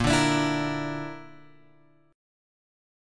A# Major Flat 5th